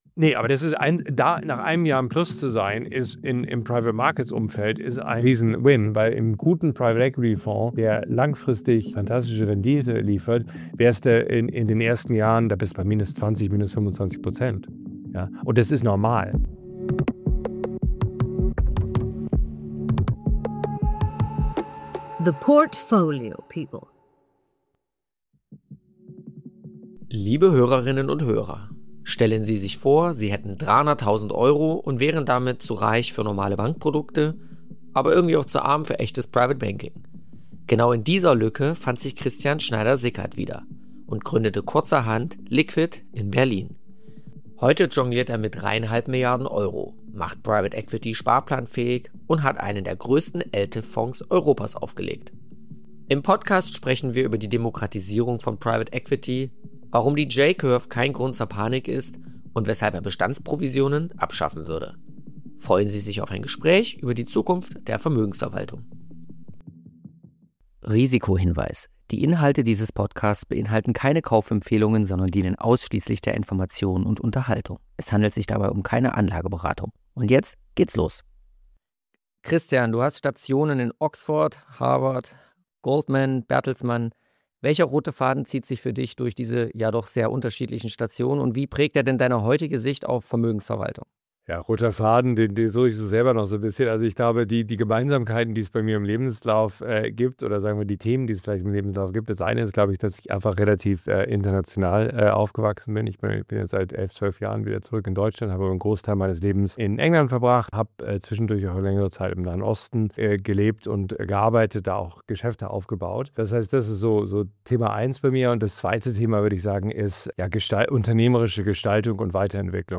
Im Gespräch erklärt er, warum die gefürchtete J-Curve bei Private Equity normal ist, wie sein Eltif-Fonds zu einem der größten Europas wurde und warum er Bestandsprovisionen für ein "Riesenproblem" hält.